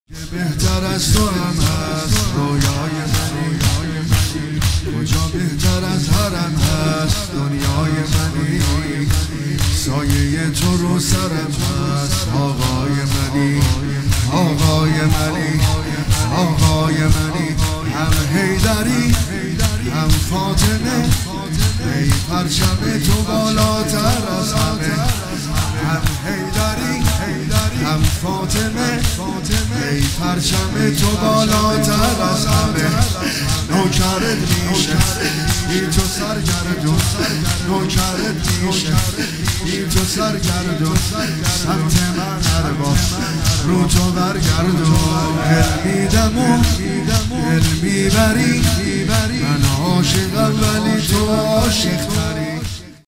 رویای منی عبدالرضا هلالی | مداحی شور | پلان 3